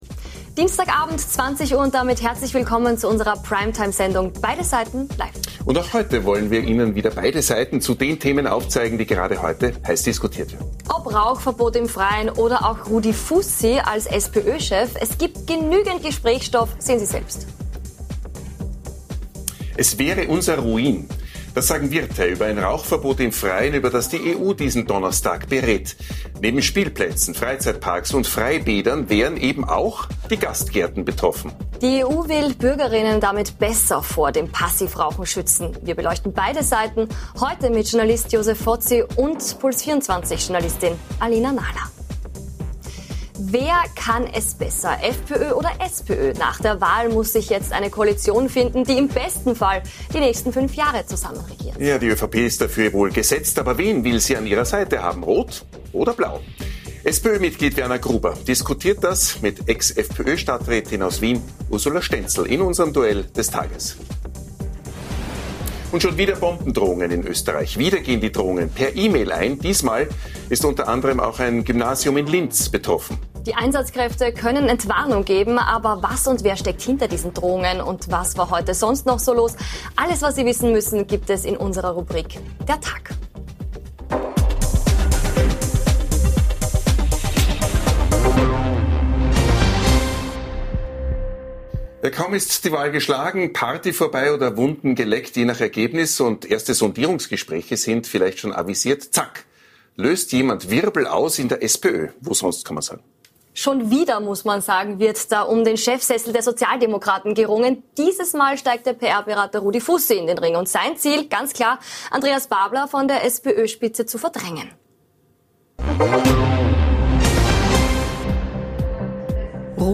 Und nachgefragt haben wir heute bei gleich zwei Gästen - im großen Beide Seiten Live Duell mit der ehemaligen FPÖ-Politikerin Ursula Stenzel und dem aktiven SPÖ-Mitglied und Physiker Werner Gruber.